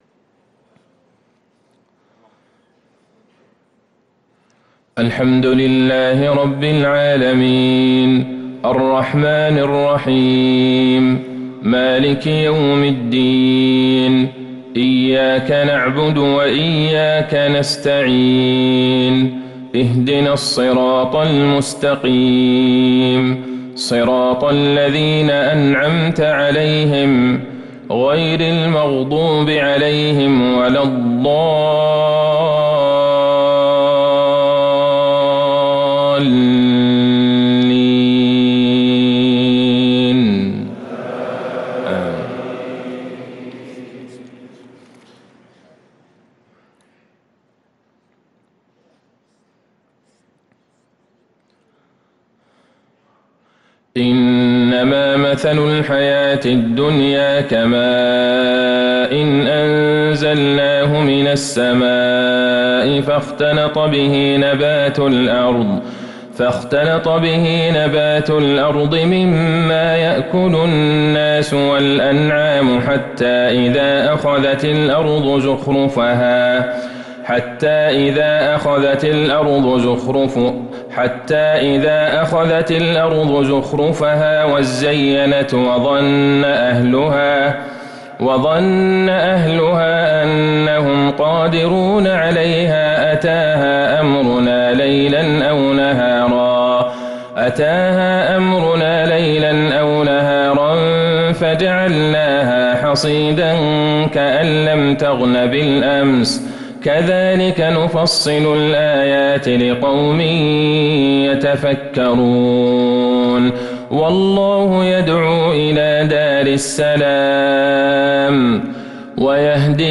صلاة الفجر للقارئ عبدالله البعيجان 3 ربيع الآخر 1445 هـ
تِلَاوَات الْحَرَمَيْن .